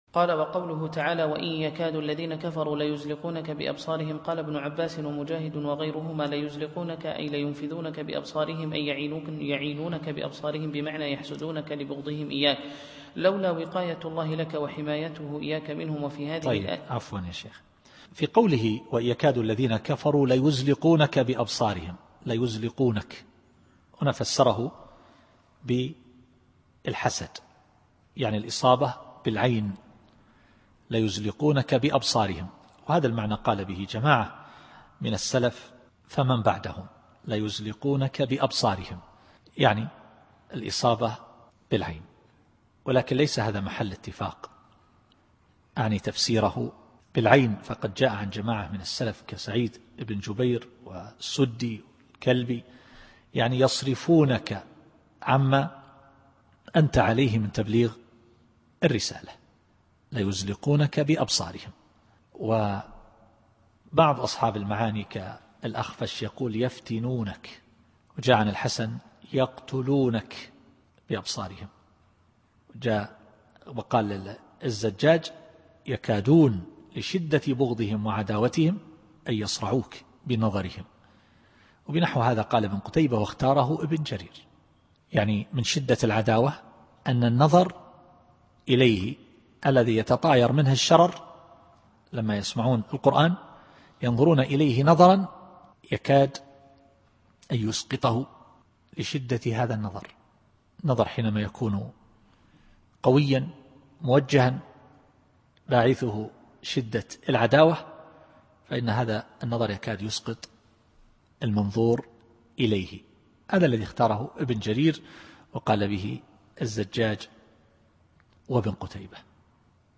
التفسير الصوتي [القلم / 51]